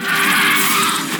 File:B-bot pteradactyl Roar.ogg
B-bot_pteradactyl_Roar.ogg